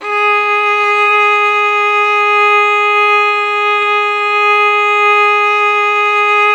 Index of /90_sSampleCDs/Roland - String Master Series/STR_Violin 4 nv/STR_Vln4 no vib
STR VLN BO09.wav